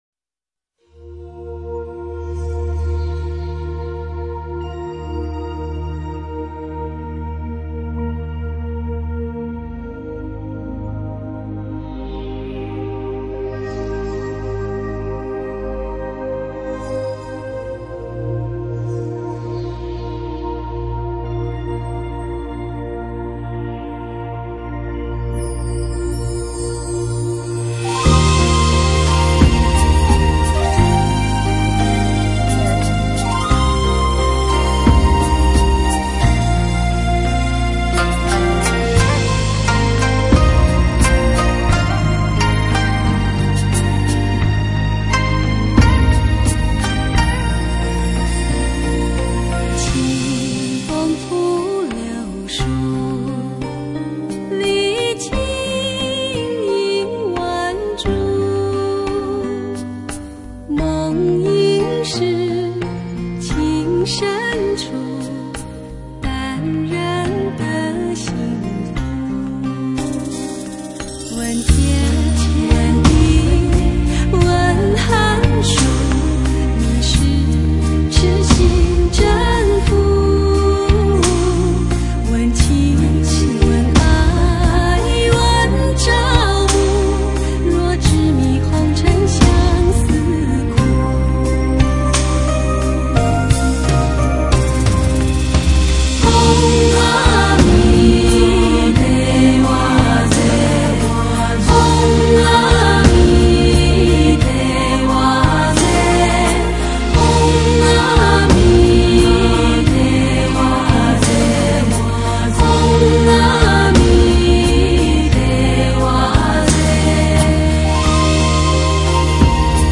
◎清新可喜的节奏与温柔人声引导着一字一句的进行，散发着敦慈的美妙能量◎
在繁忙的现代生活中聆听精致而独特的全新音乐，你可以很舒服地随着旋律而放松，
冥想让心像海洋一样宽阔无比。强烈的感动元素令人生起一种莫名的慈爱与怀柔的念头。